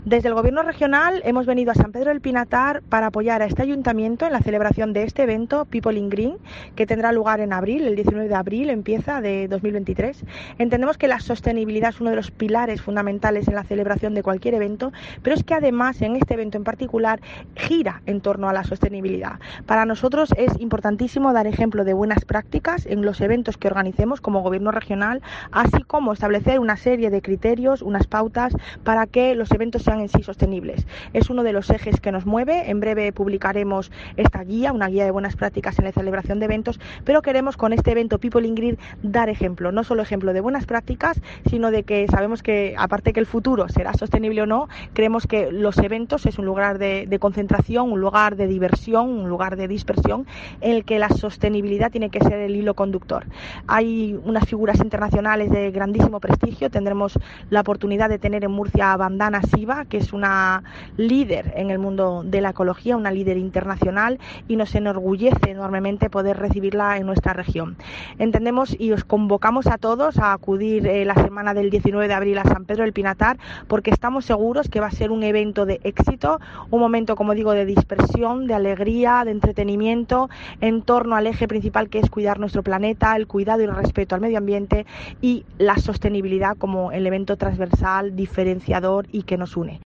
María Cruz Ferraira, directora general del Medio Natural